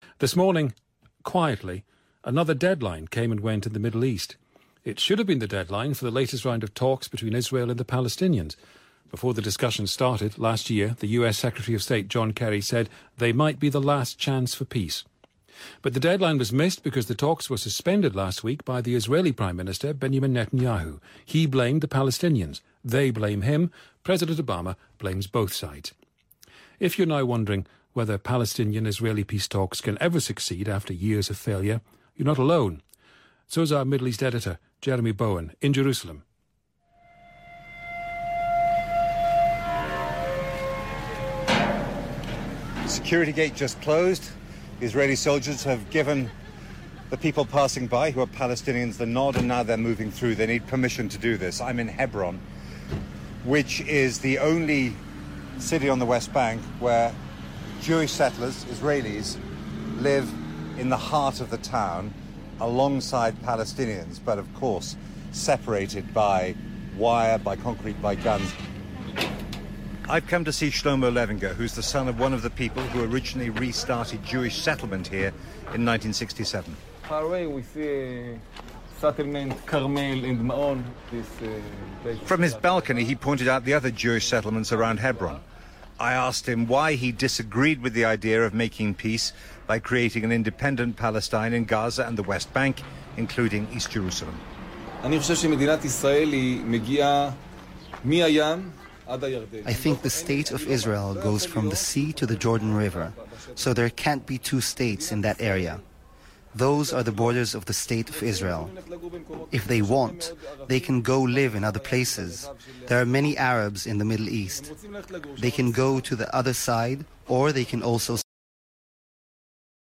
BBC's Jeremy Bowen report on a 2 State Solution - no alternative is easier, and many look much worse.